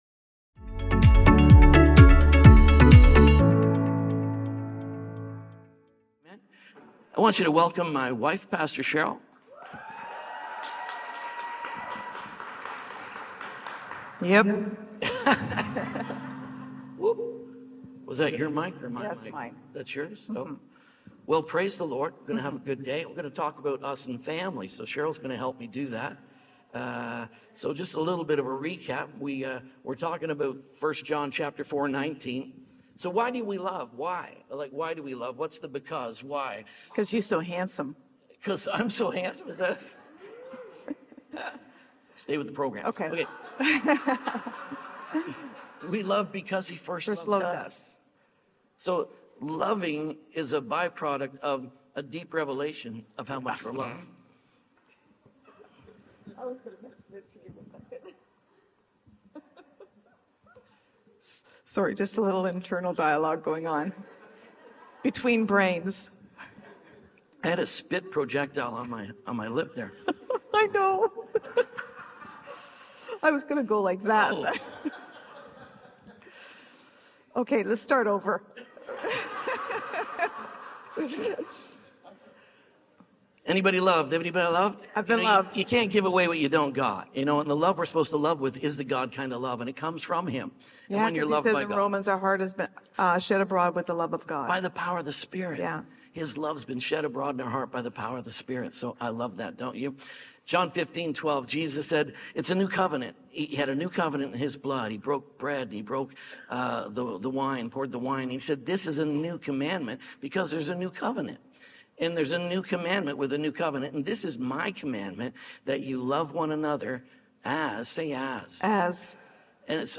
Us-and-Family-|-Us-Series-|-SERMON-ONLY.mp3